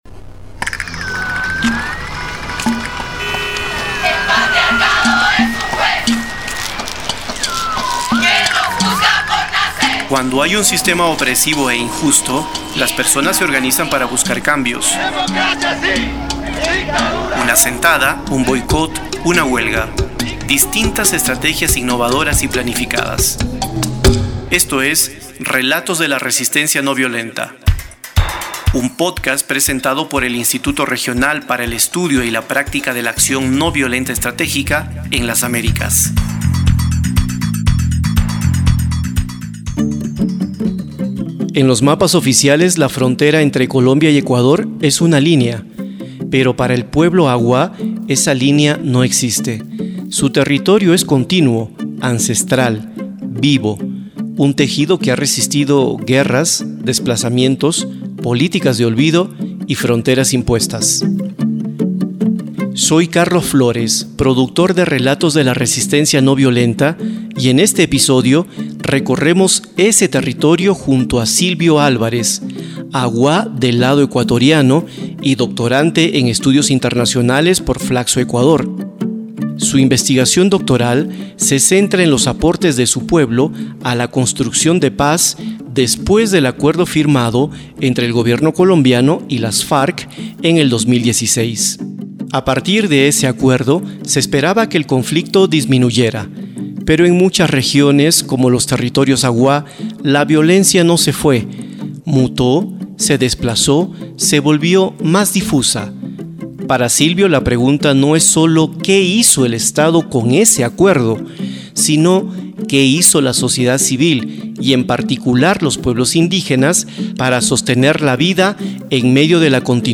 Tamaño: 47.90Mb Formato: Basic Audio Descripción: Entrevista - Acción ...